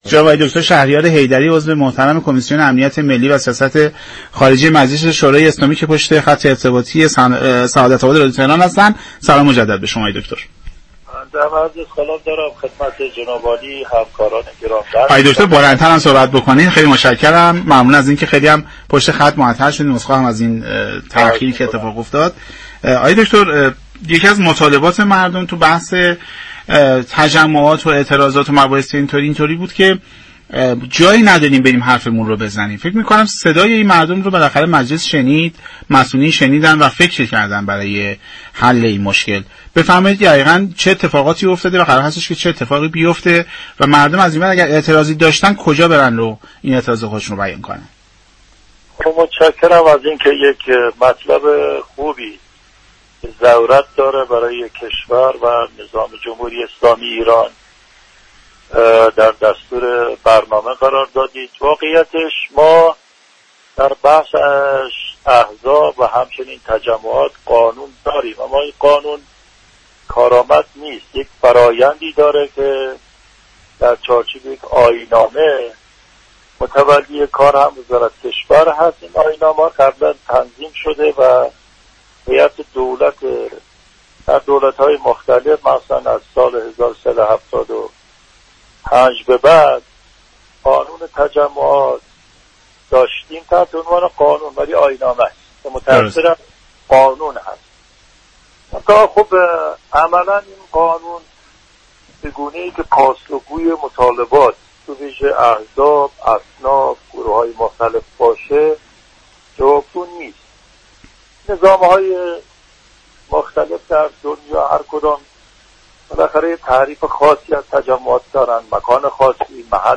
شهریار حیدری عضو كمیسیون امنیت ملی و سیاست خارجه مجلس یازدهم در گفتگو با برنامه سعادت آباد